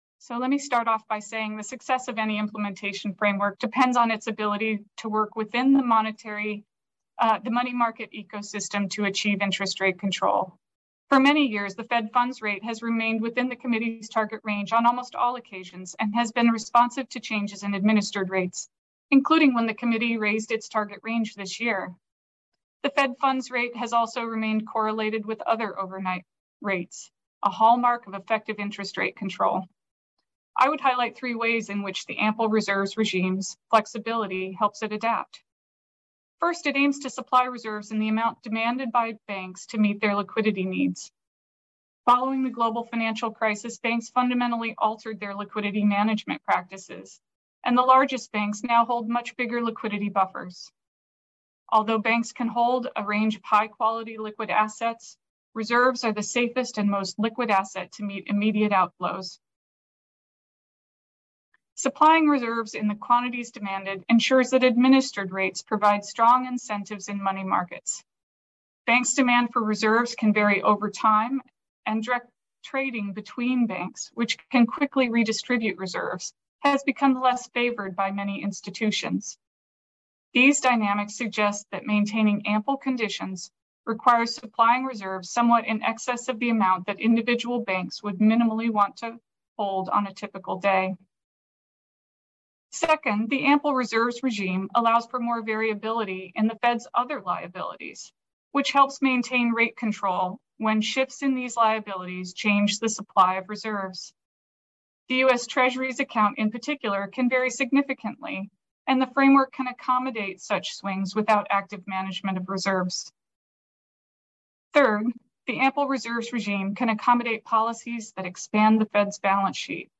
Panel 2: The Fed’s Operating System and New Monetary Framework: An Appraisal — Cato Institute 40th Annual Monetary Conference